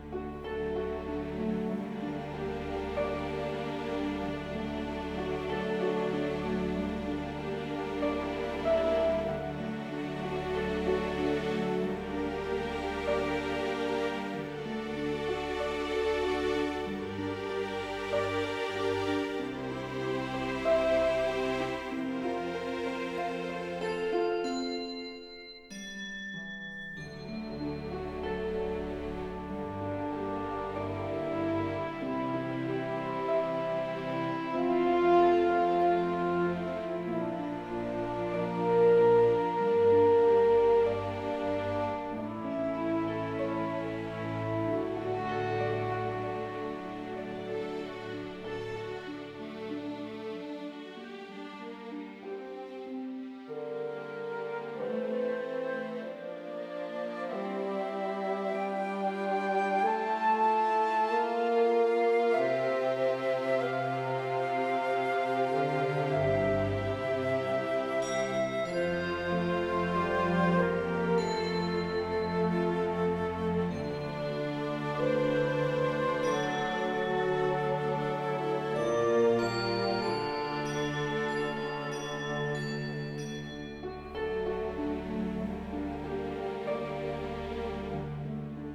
ambient tracks
relaxed.wav